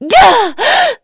Veronica-Vox_Attack4.wav